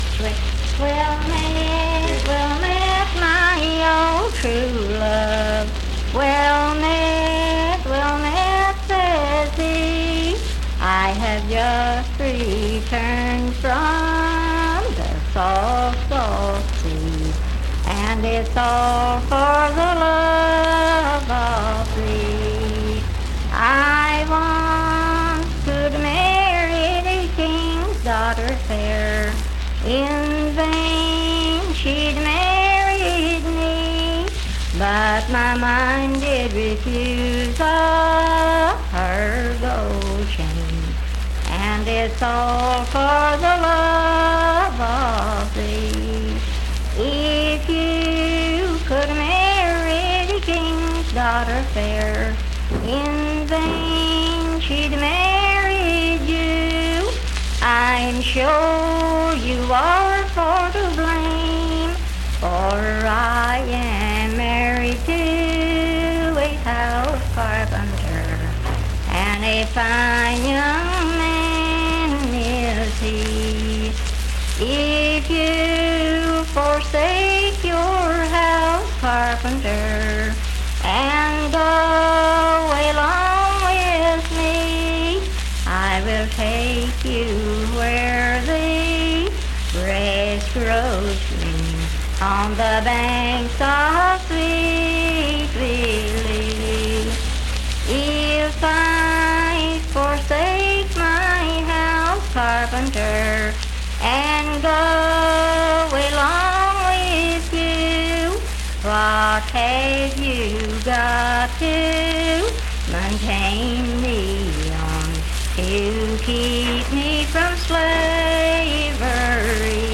Unaccompanied vocal music
Voice (sung)
Spencer (W. Va.), Roane County (W. Va.)